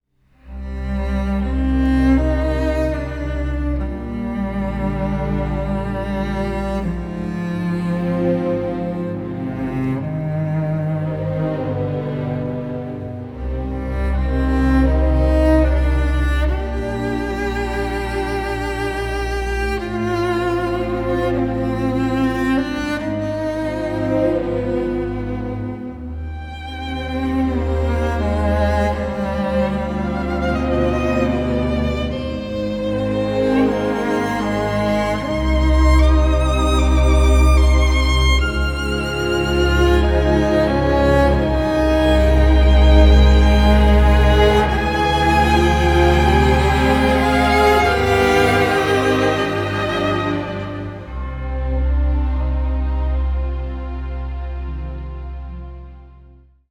delivers a poignant and deeply moving symphonic score